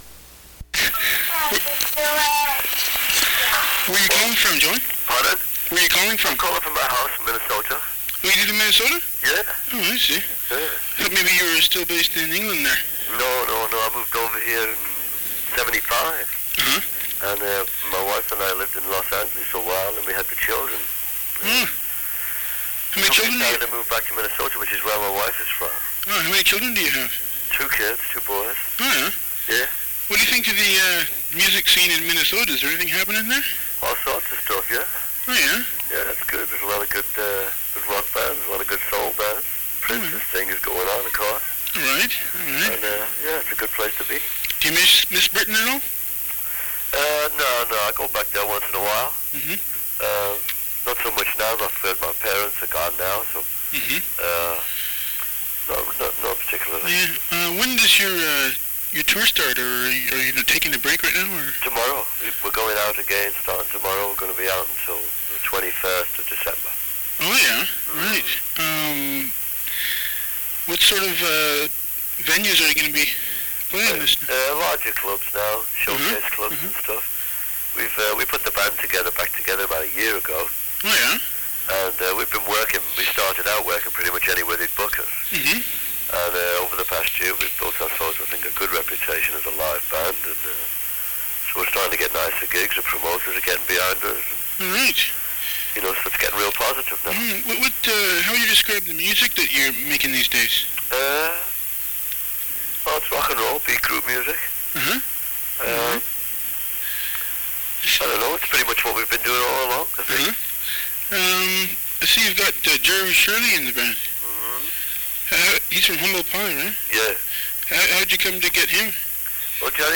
Ten minutes on the phone with Joey Molland of Badfinger (R.I.P.)
He called me from his home in Minnesota back in November of 1987, when Badfinger was headed to Vancouver for two nights at the Commodore Ballroom. Although the history of Badfinger was mired in tragedy–including the suicides of band members Pete Ham and Tom Evans–Molland kept a positive outlook, as you can hear in his voice.